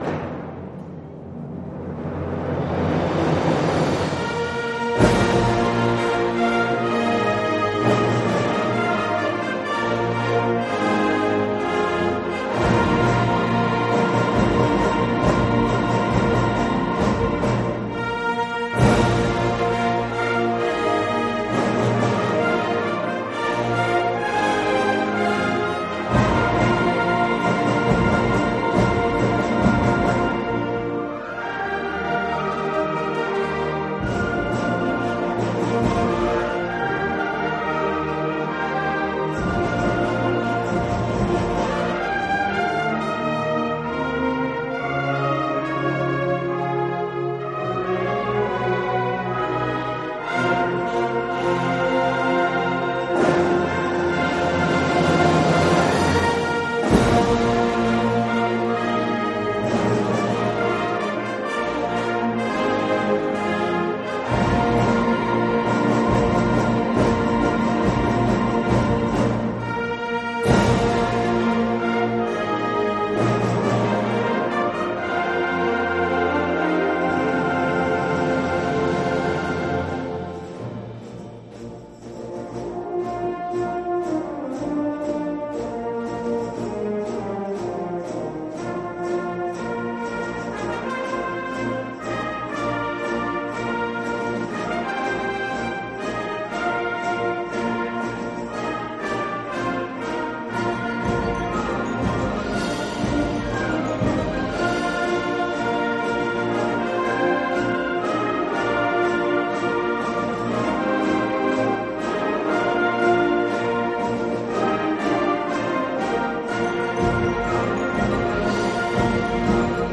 Genre musical : Classique
Collection : Harmonie (Orchestre d'harmonie)
Oeuvre pour orchestre d’harmonie.